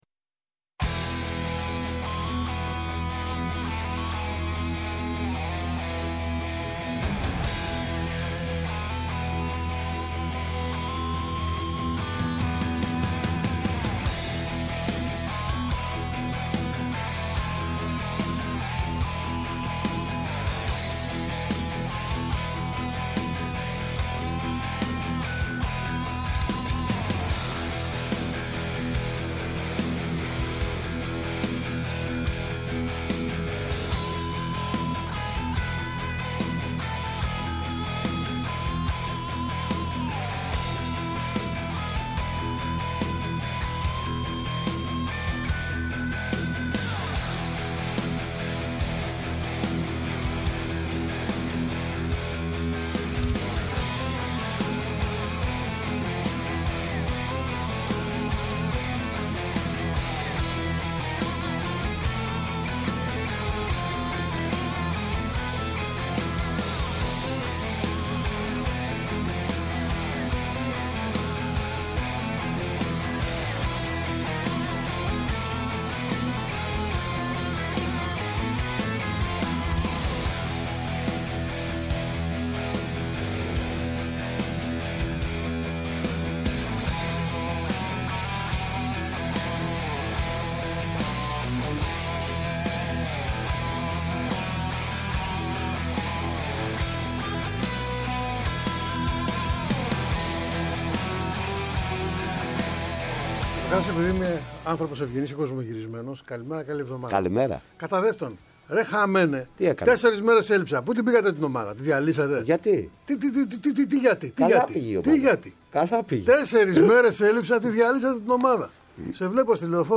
Επιτρέπεται μάλιστα η είσοδος στο στούντιο σε κάθε λογής περαστικούς!